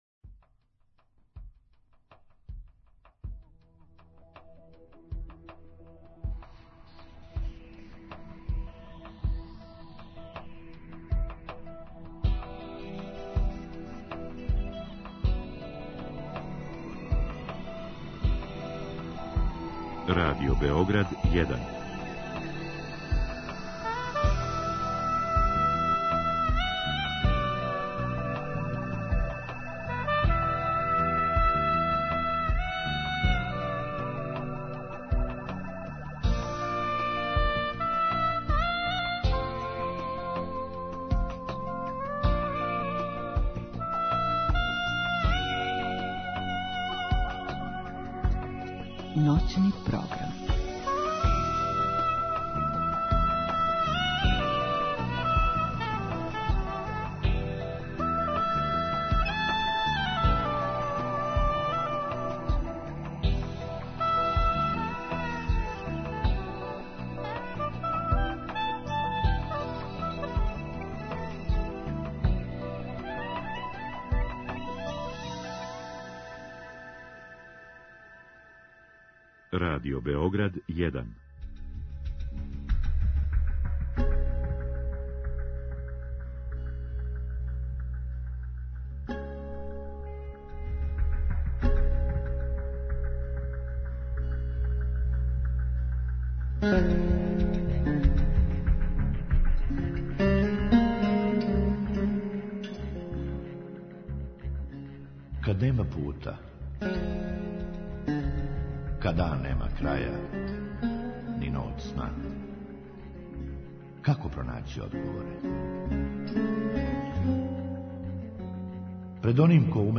У другом сату слушаоци у директном програму могу поставити питање гошћи.